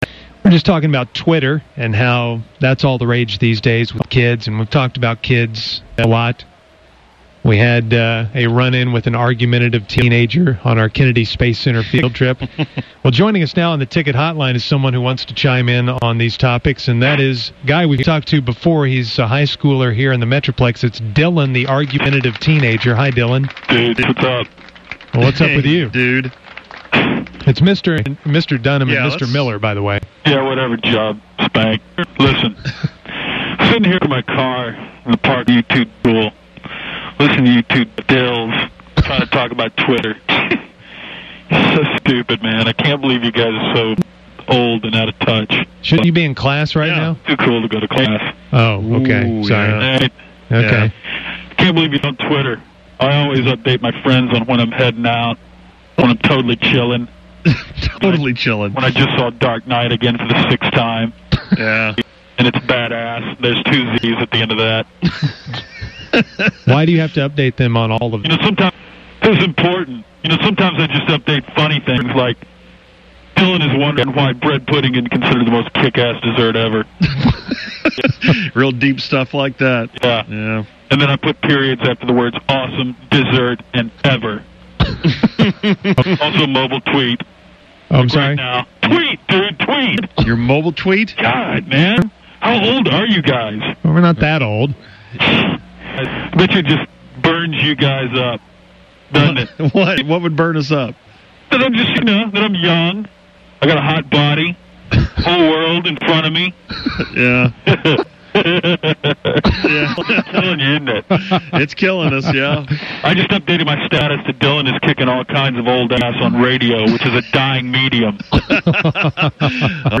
Then makes weird noises.